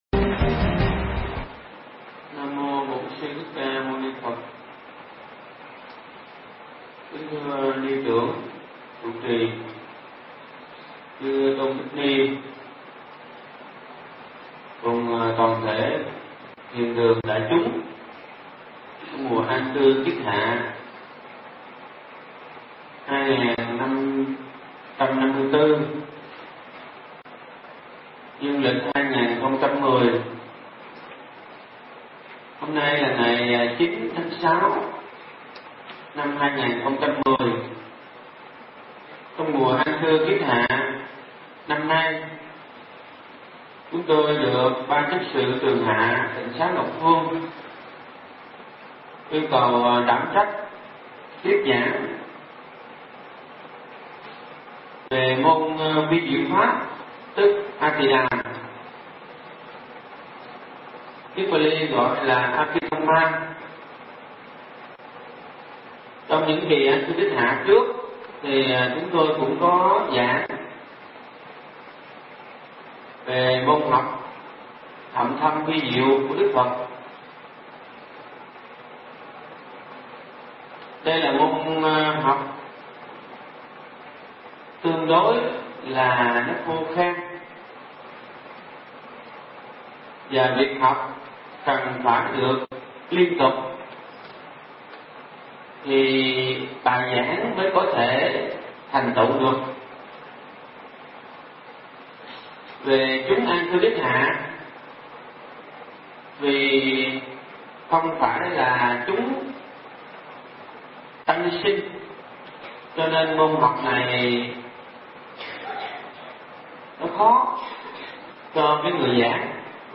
Nghe Mp3 thuyết pháp Vi Diệu Pháp
Nghe mp3 pháp thoại Vi Diệu Pháp